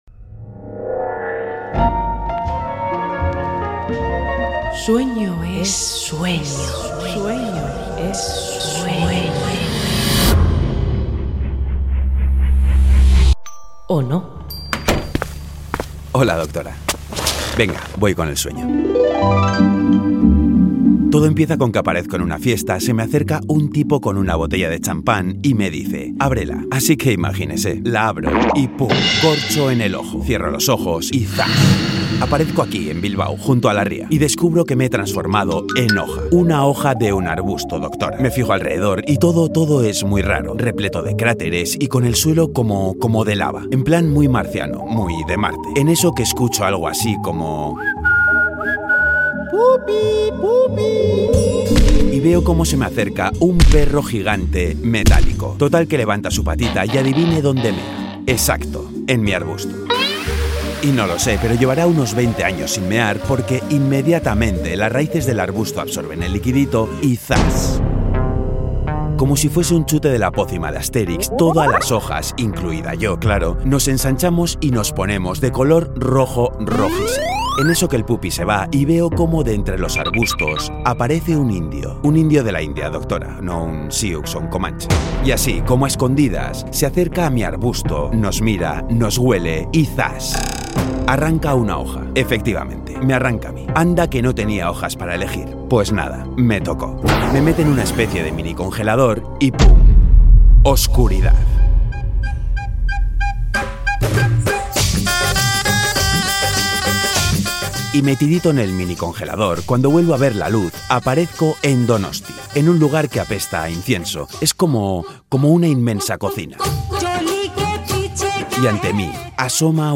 Audio: Ficción sonora que firma para el Boulevard Magazine